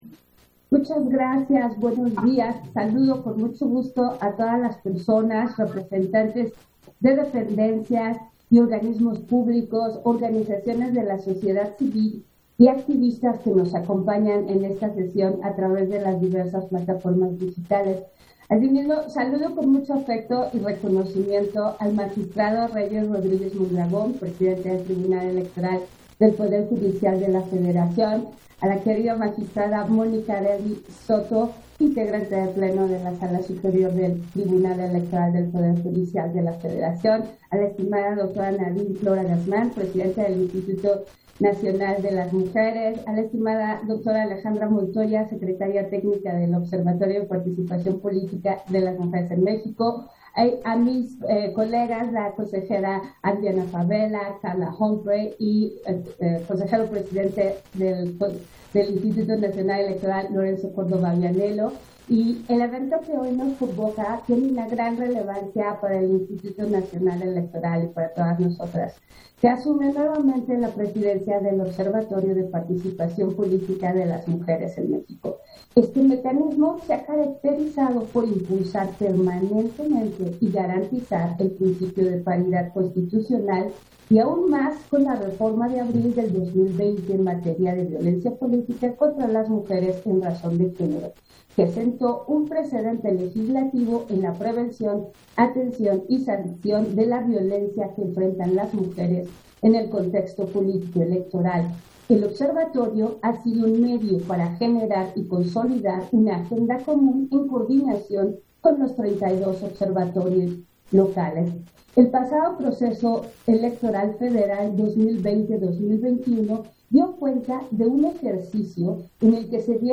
Intervención de Norma de la Cruz, en la sesión protocolaria, de entrega de Presidencia y Secretaría Técnica del Observatorio de Participación Política de las Mujeres en México